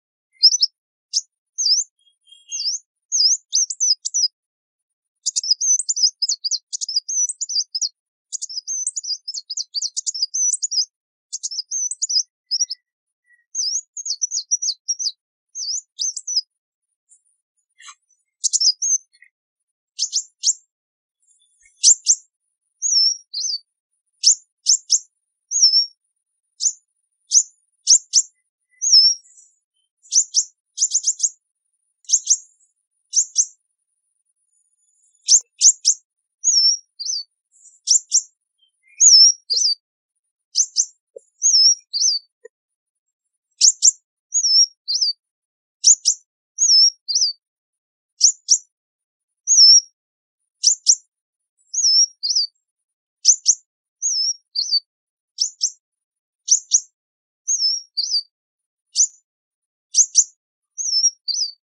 Tiếng chim Hút Mật MP3